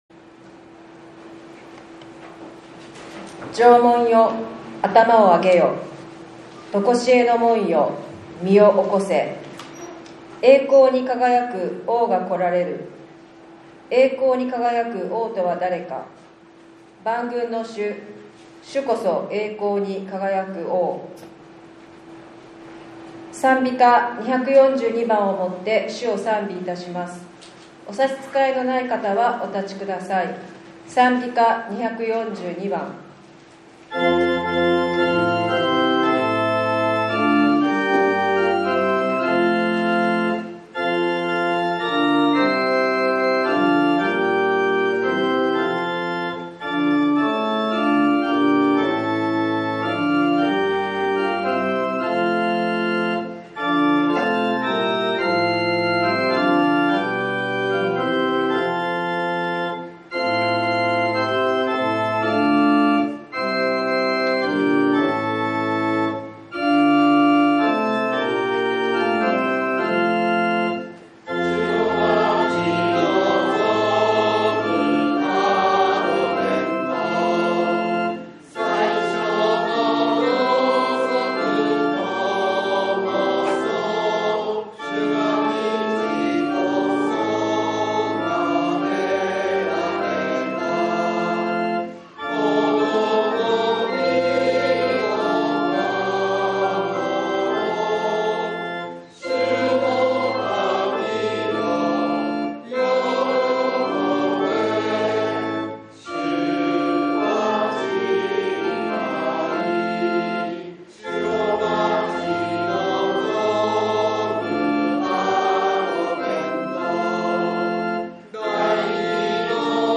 １２月２２日（日）クリスマス礼拝